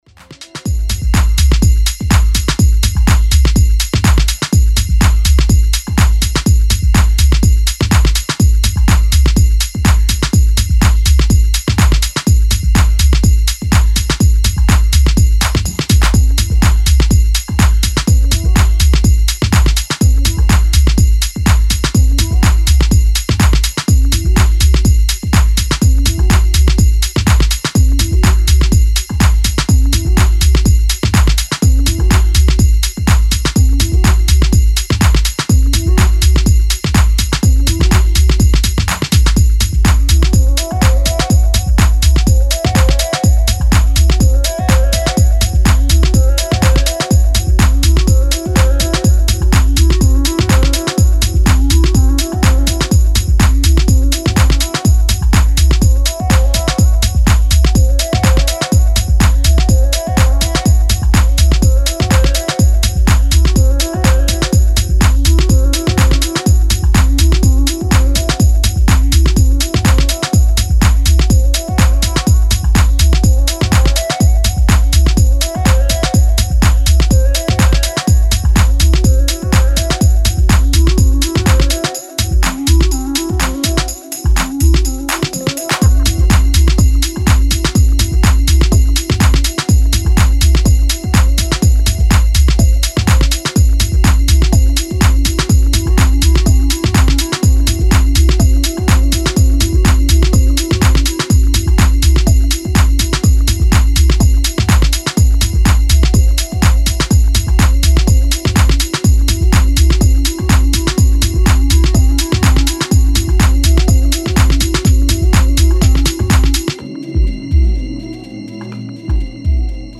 Style: Techno